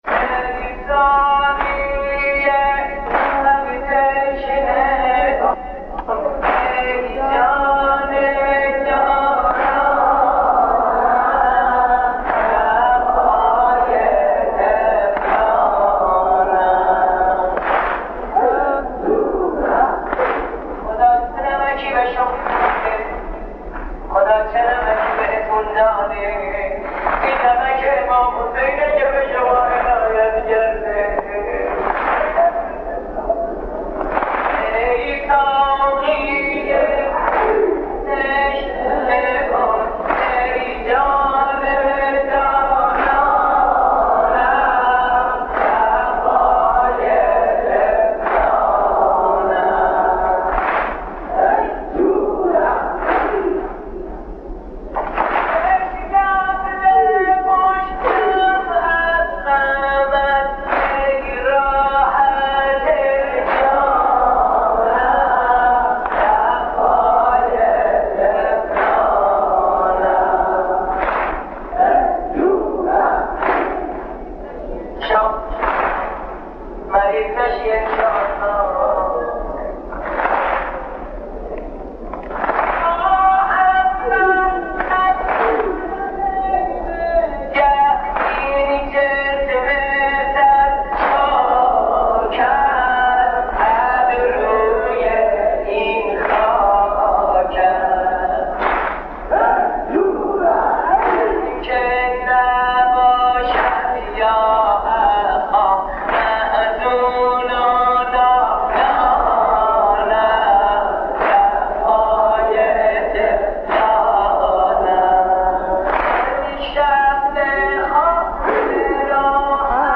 در دل شب‌های محرم، نغمه‌هایی بودند که با سوز دل مداحان قدیمی، راه دل را به کربلا باز می‌کردند.
نوحه‌خوانی